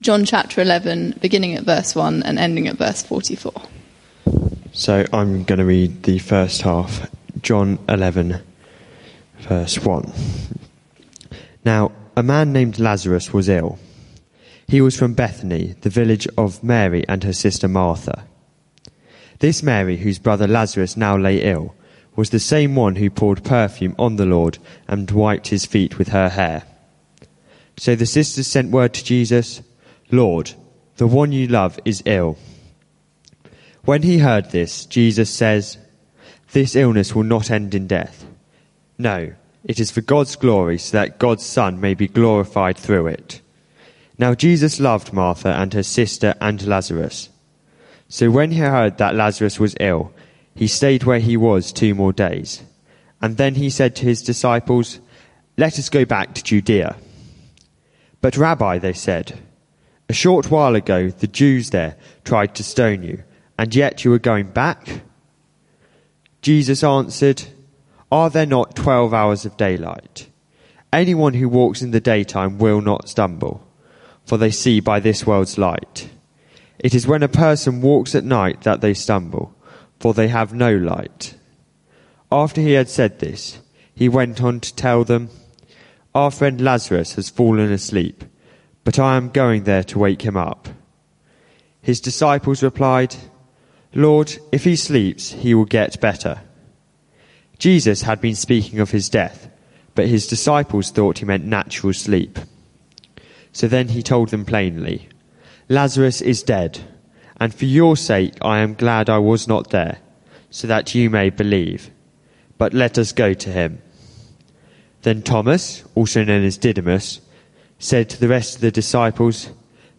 This sermon is part of a series: 5 June 2016